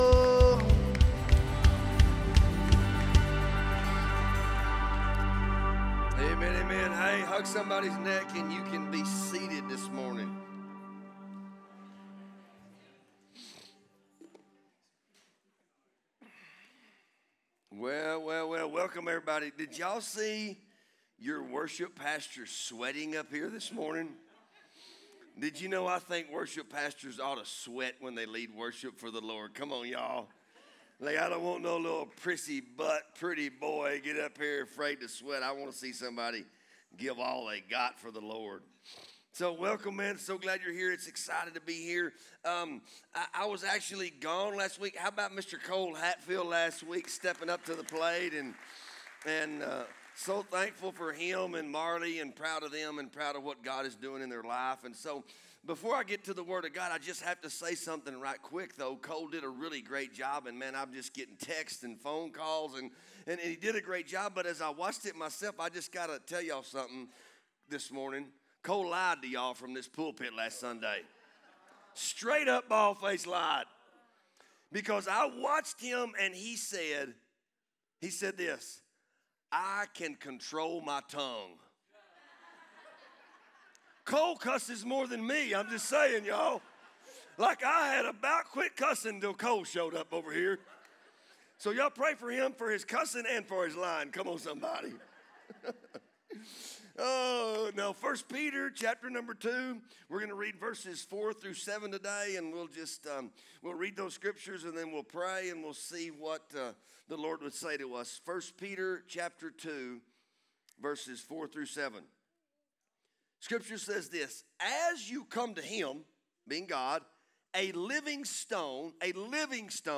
Sermons | Bethel Assembly of God Church